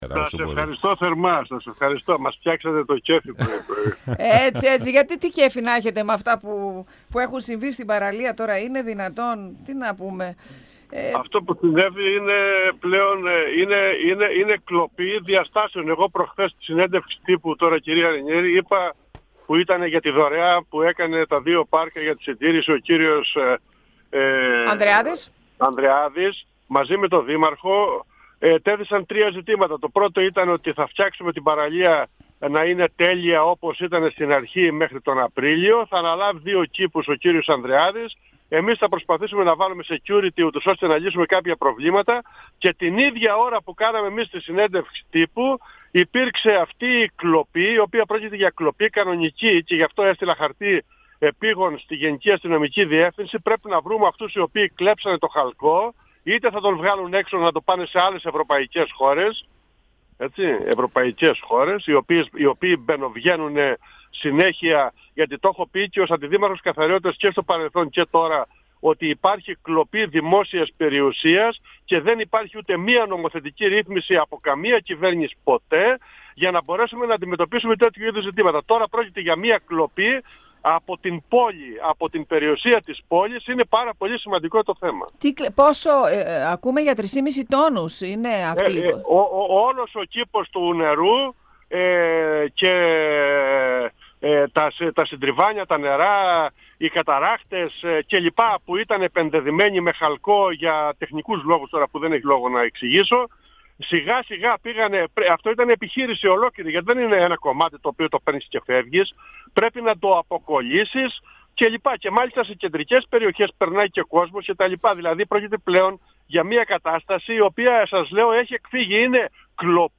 Ο αντιδήμαρχος Τεχνικών Εργων Θανάσης Παππάς, στον 102FM του Ρ.Σ.Μ. της ΕΡΤ3